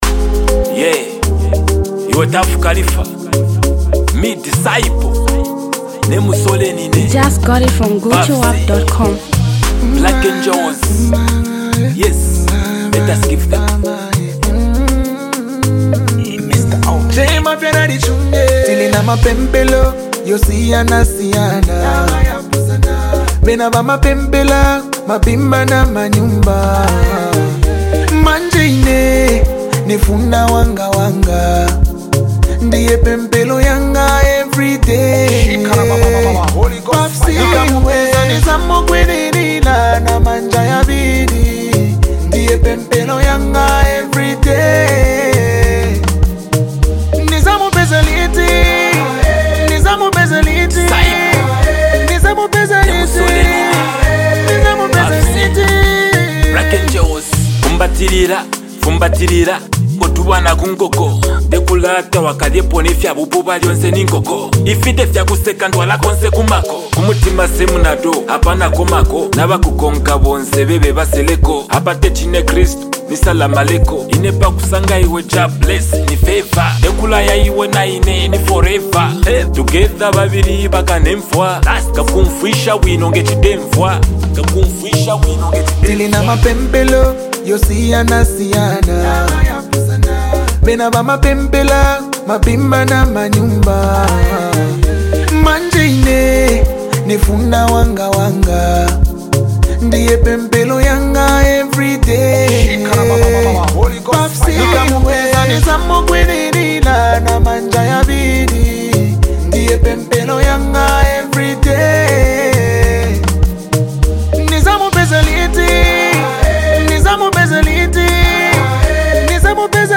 Zambian Mp3 Music
street lovable anthem record